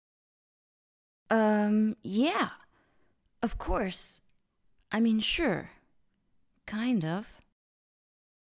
Texte lu en anglais par l'auteure